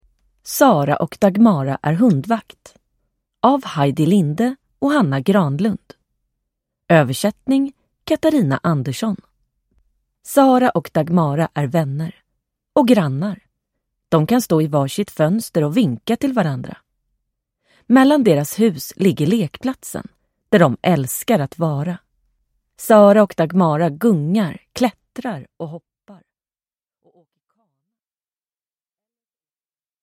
Sara och Dagmara är hundvakt – Ljudbok